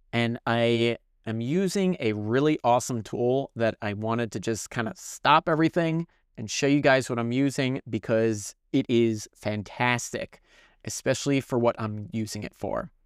It’s called Podcastle, and they have a paid feature ($14 for a month is a good deal in my book) called Magic Dust AI which analyzes the audio and fixes it beautifully.